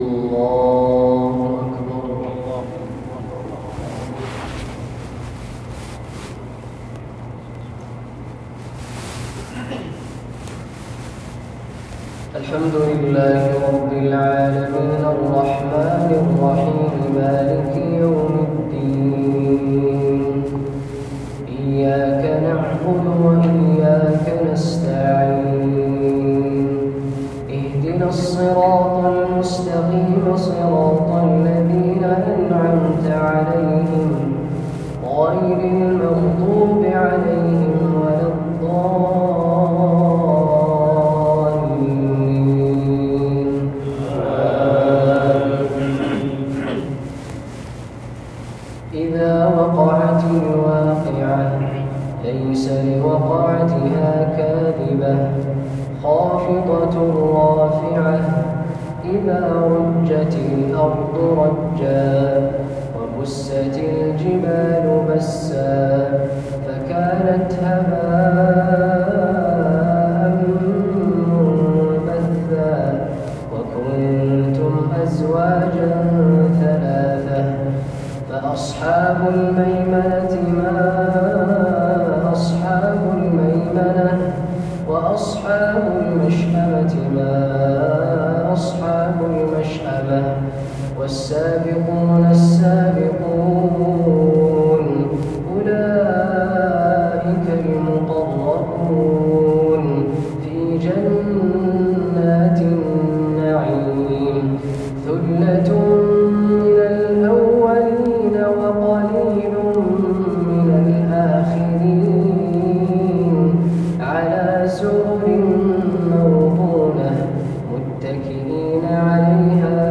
عشائية خاشعة
بجامع مهل الرحيلي، بحي الخاتم بالمدينة النّبوية.